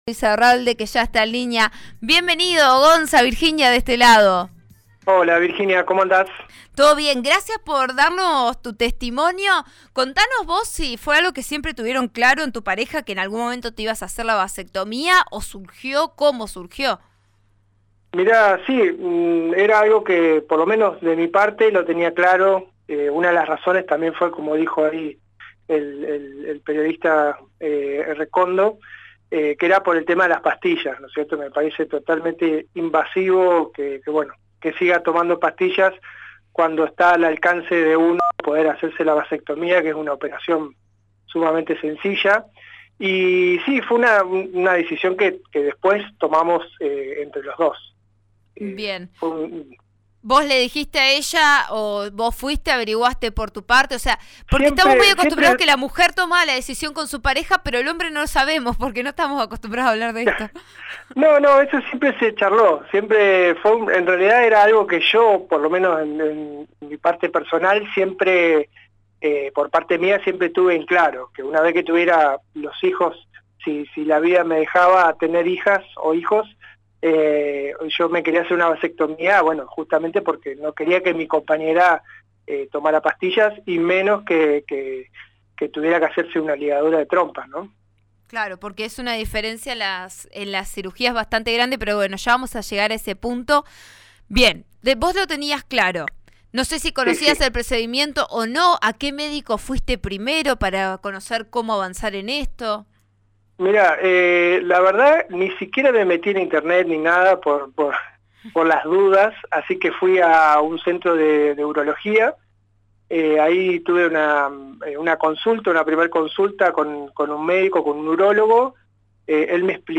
en conversación con «Vos A Diario» (RN RADIO 89.3).